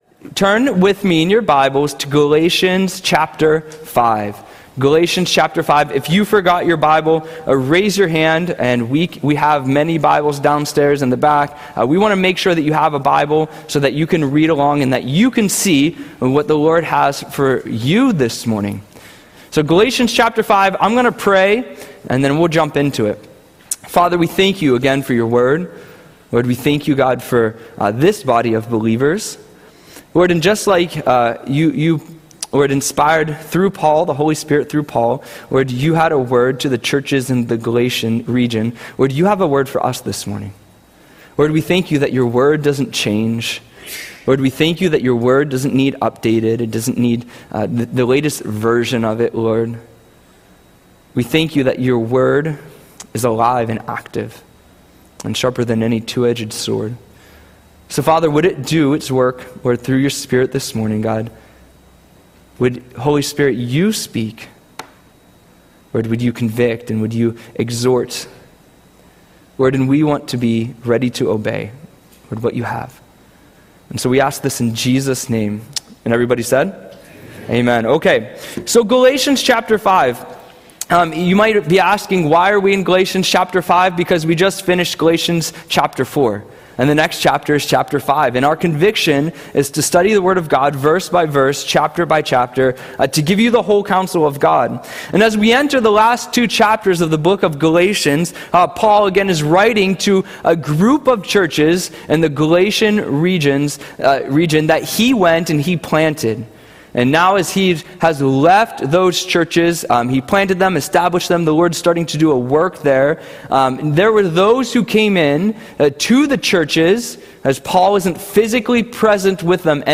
Audio Sermon - June 20, 2025